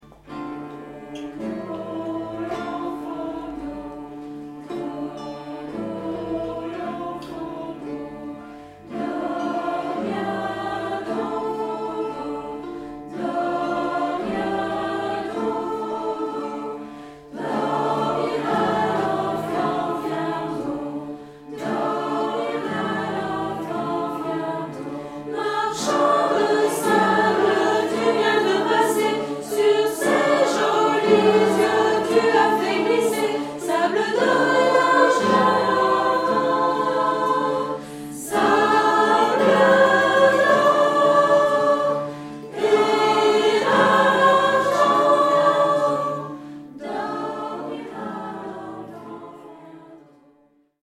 Un canon sur l’air de la chanson traditionnelle Frère Jacques, mais en mode mineur, par-dessus lequel vient se superposer une ligne mélodique plus complexe.
Une chanson qui est idéale pour une chorale regroupant tous les niveaux de classe : le canon est très simple, la 2ème voix beaucoup plus complexe.